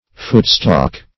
Footstalk \Foot"stalk`\, n.